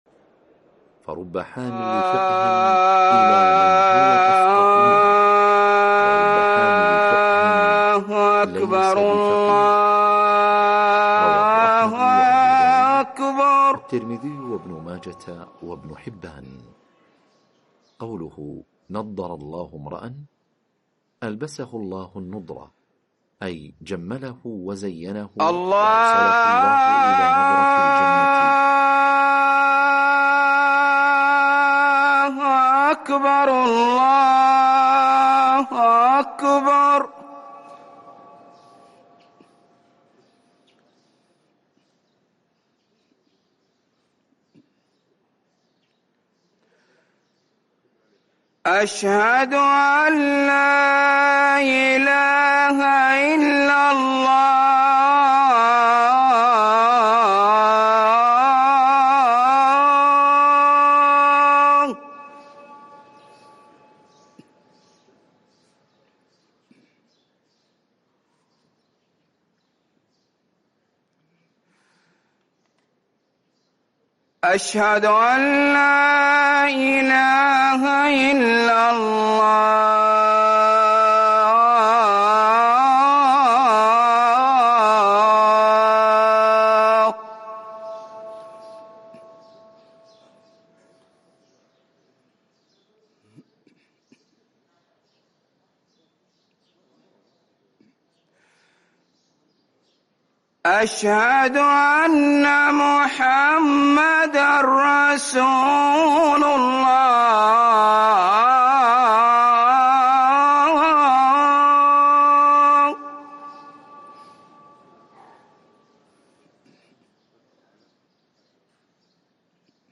أذان الجمعة الأول